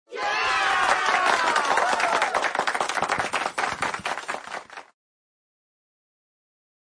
Crowd Sound Button - Free Download & Play